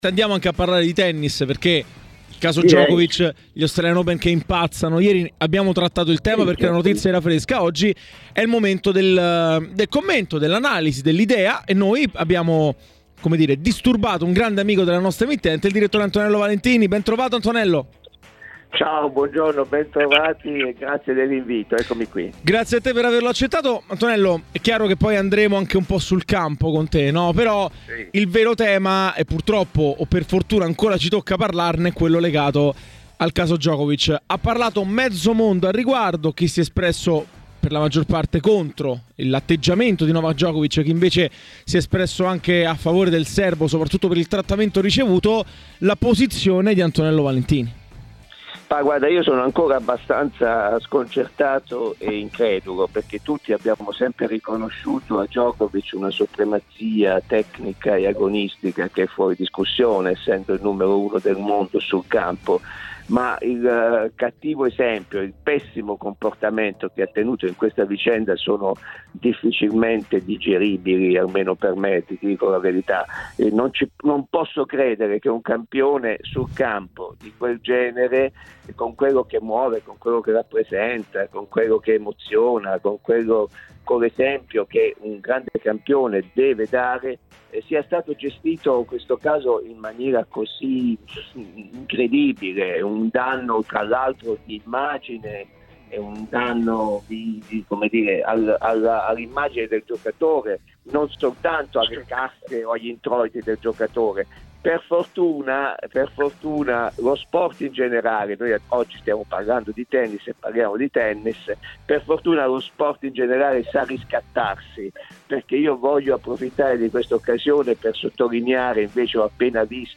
è intervenuto in diretta a 'Pianeta Sport', su TMW Radio, per commentare il caso Djokovic: